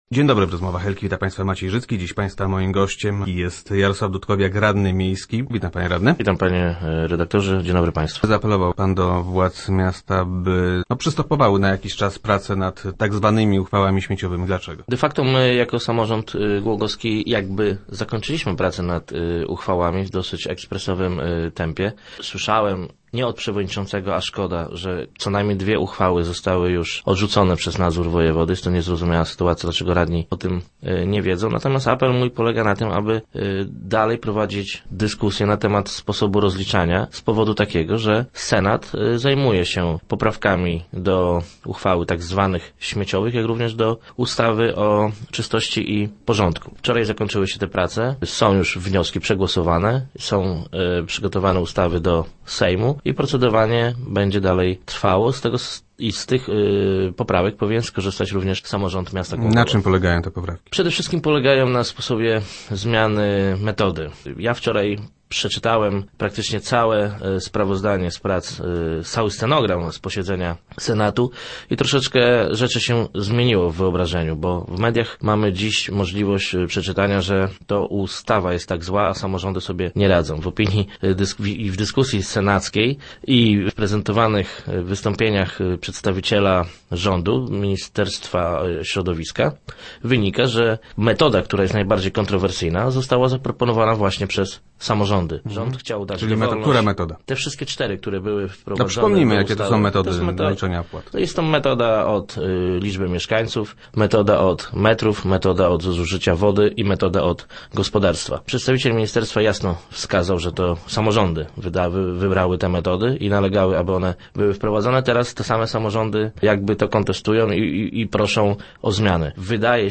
Jednak jak mówił w dzisiejszych Rozmowach Elki Jarosław Dudkowiak, radny PO, dotarły do niego informacje, że niektóre z nich zostały zakwestionowane przez nadzór wojewody.